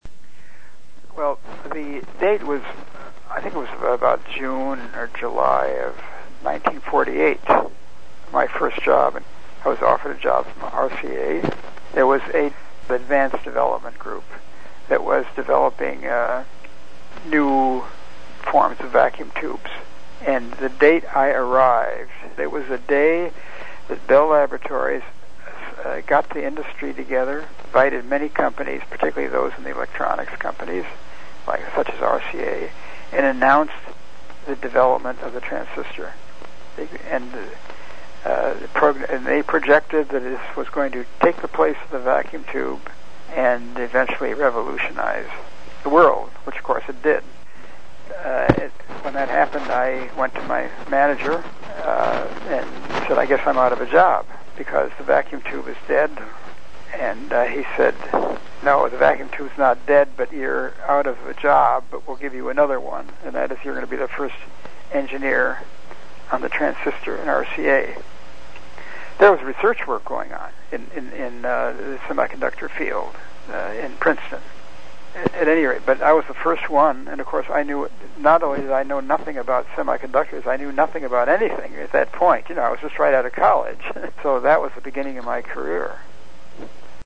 from a 2005 Interview with